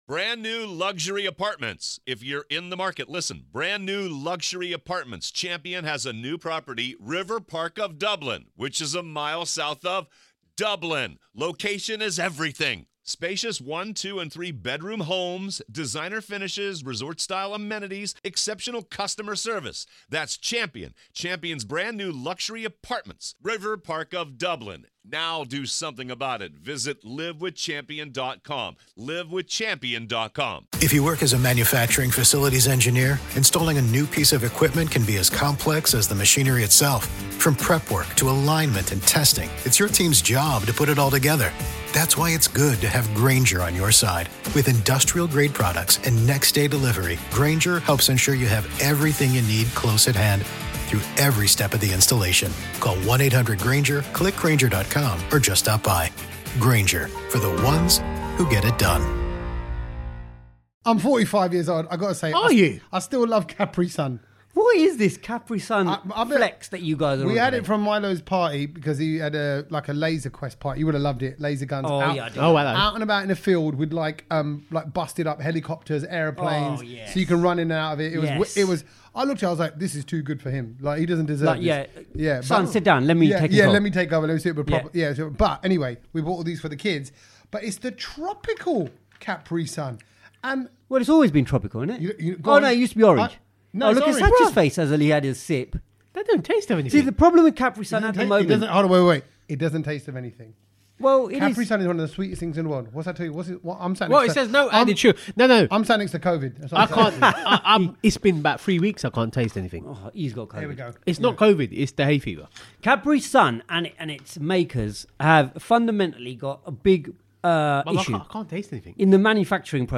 The heat is on and we are in my sweaty garage … too close for comfort!